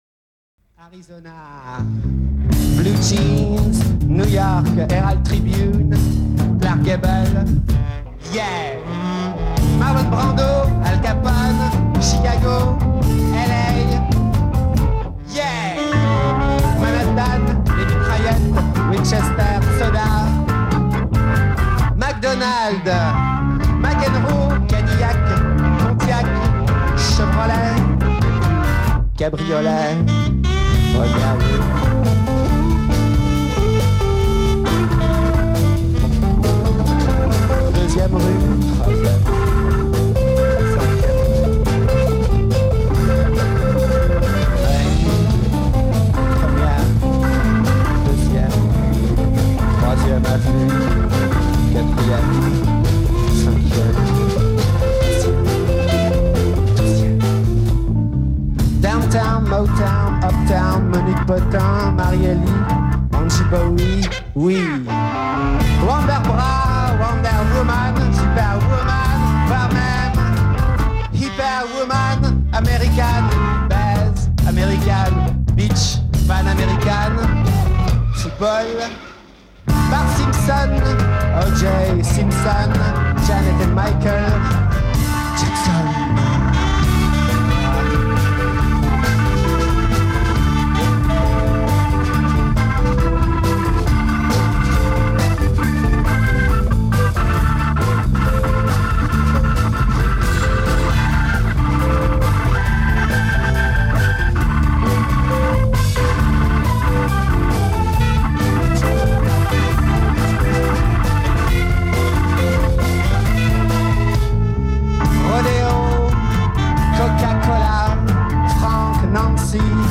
enregistrée le 12/03/2001  au Studio 105